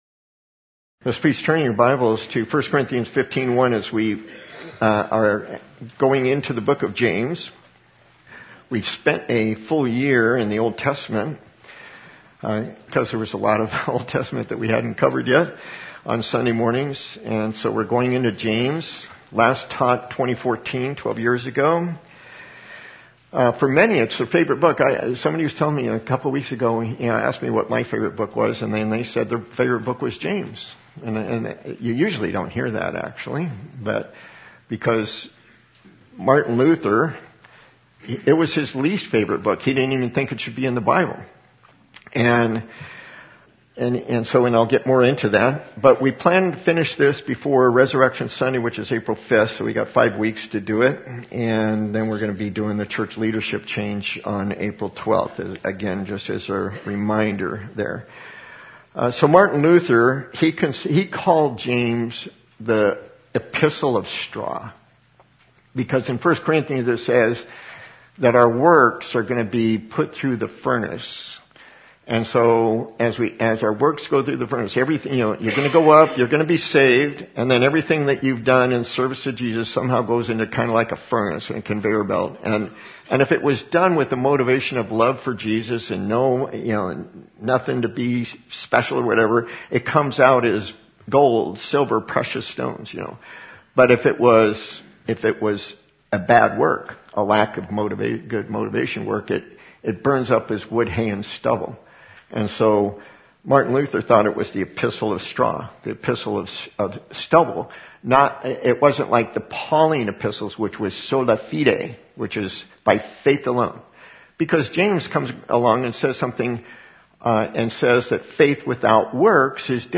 Audio file includes communion service at the end